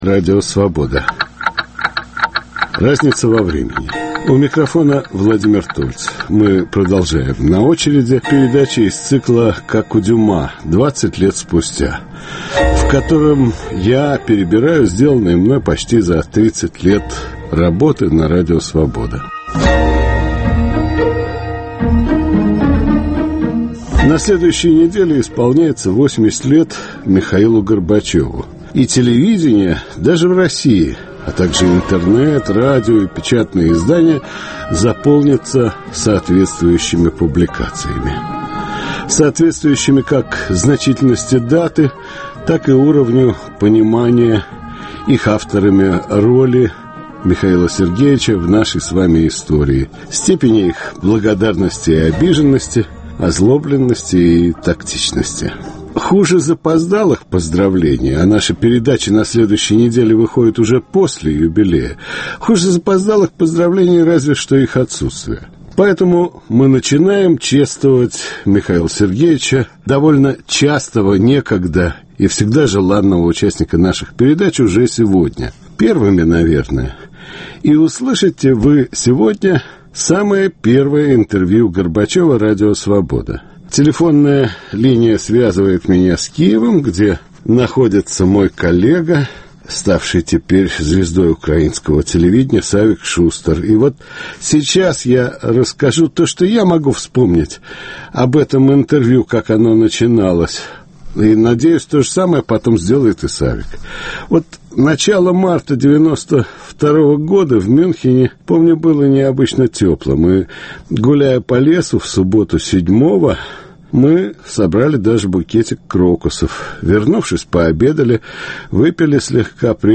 у микрофона участники интервью 1992 г.